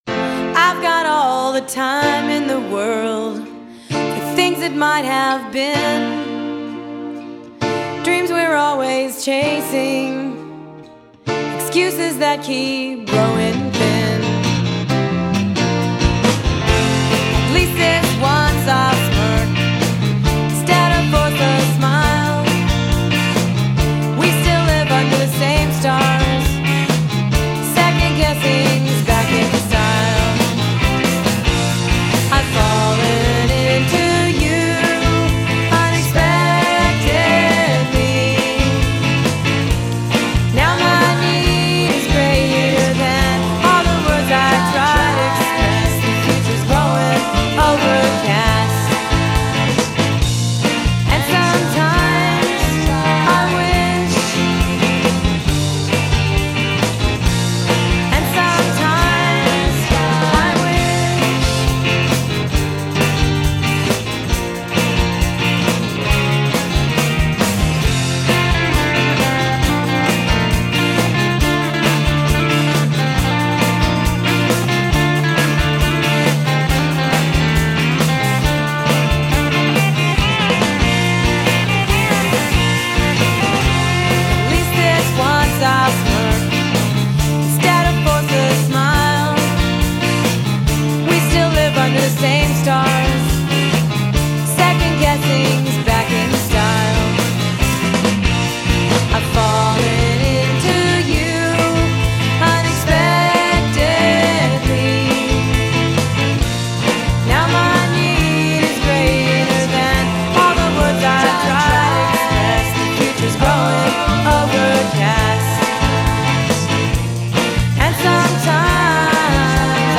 the Beatlesque